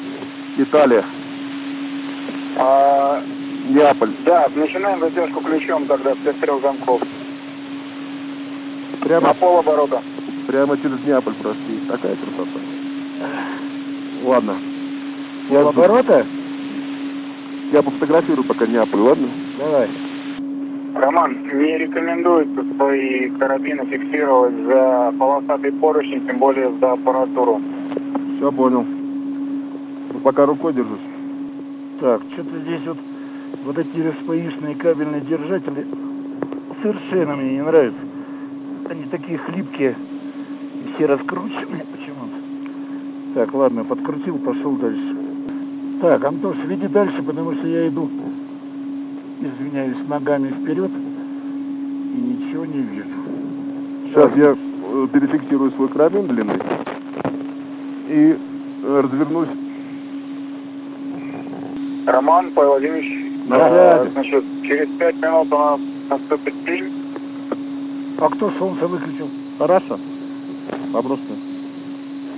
Выход в открытый космос